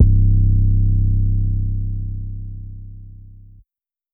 Leaux 808.wav